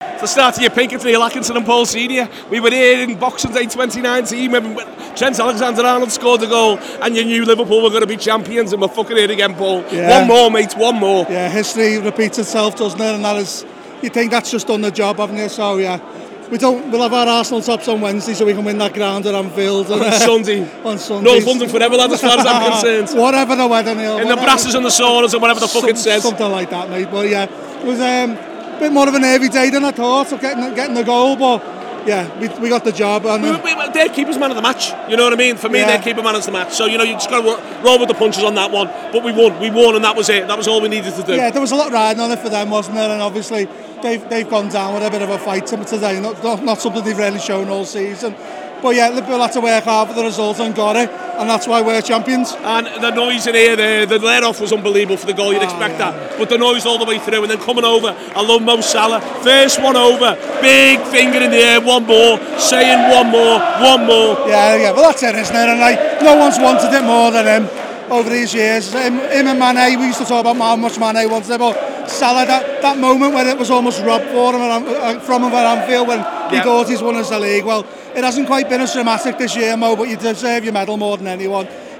The Anfield Wrap’s post-match reaction podcast after Leicester City 0 Liverpool 1 at the King Power Stadium.